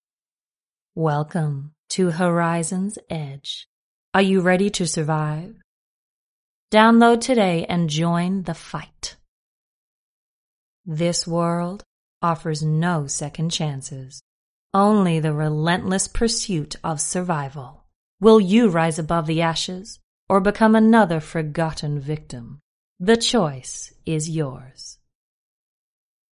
Female
Warm, expressive and naturally engaging, with a clear, bright tone that carries emotional nuance. I specialise in a smooth, conversational delivery that feels authentic and approachable, while still offering the clarity and control needed for professional narration.
Video Games
Usa Accent Game Ad